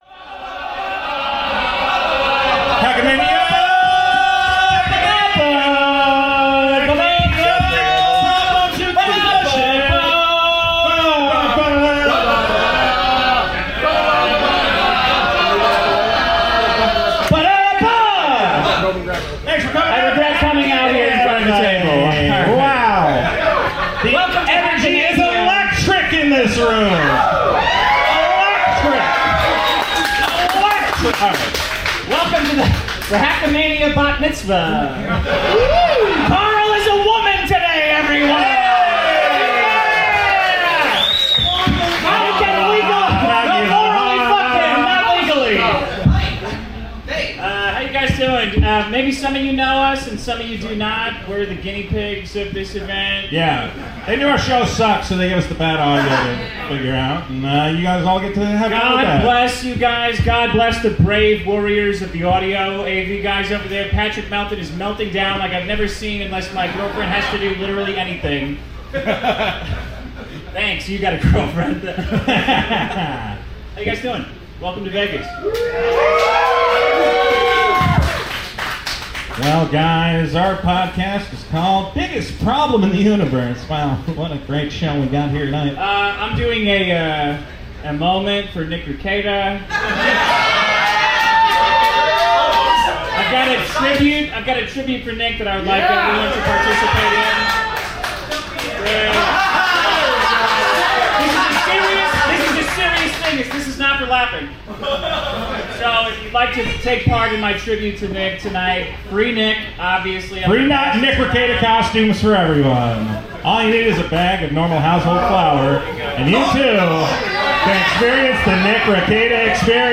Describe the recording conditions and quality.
Tags: live show